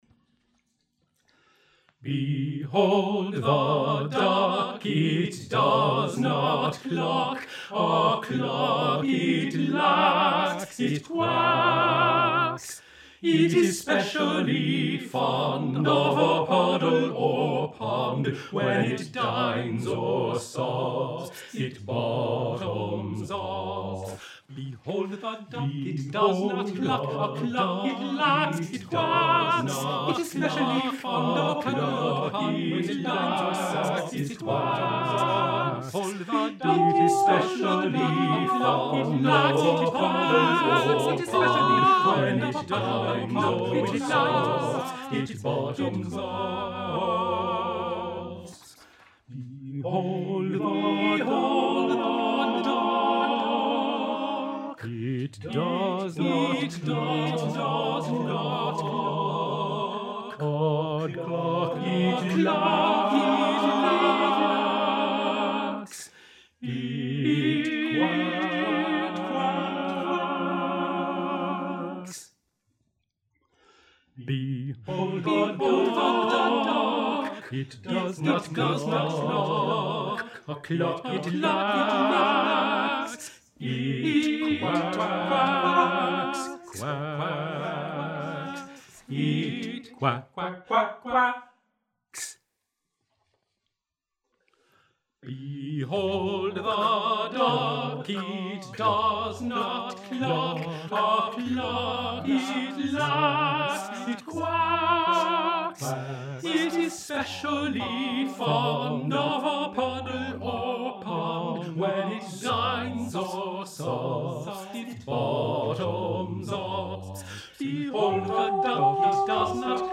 TTBB Chorus a cappella